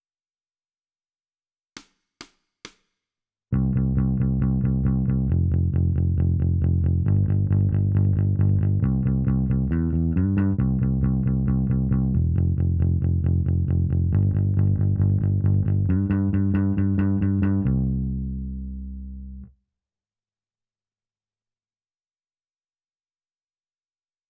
※曲の中には、無音部分が入っていることもあります。